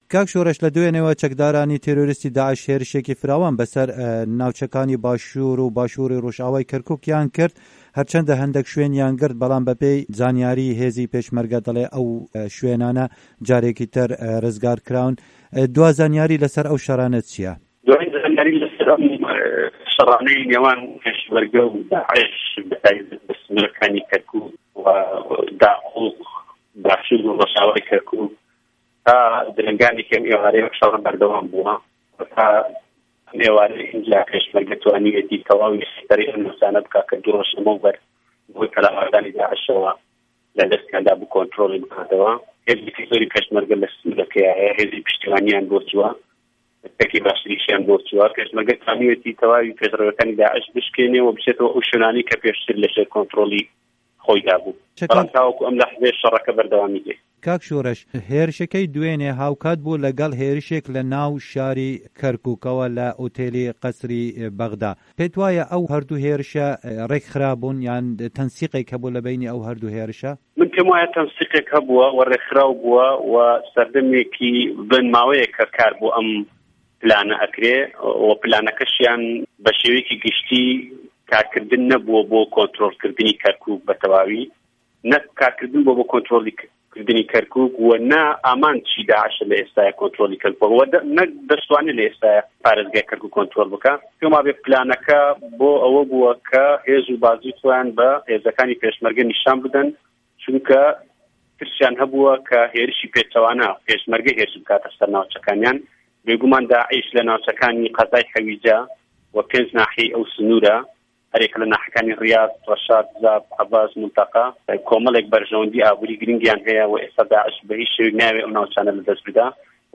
Di hevpeyvînekê de ligel Dengê Amerîka